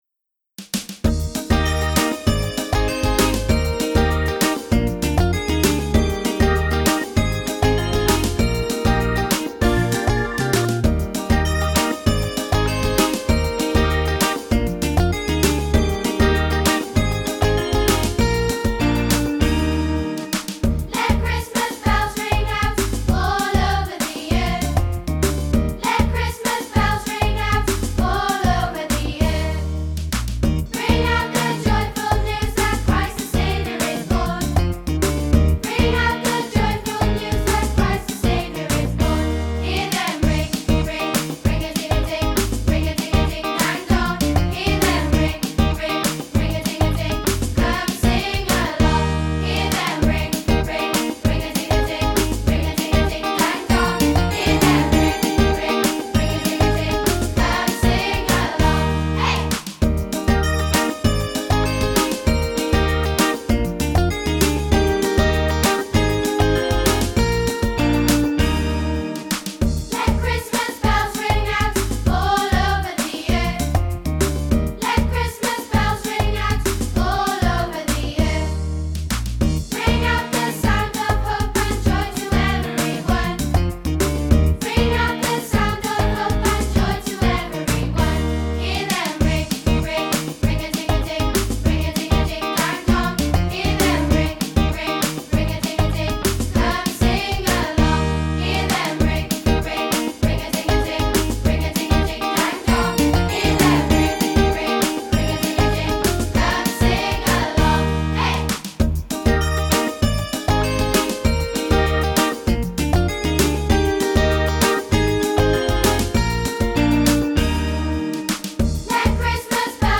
Key: E flat
Vocal range: D - B flat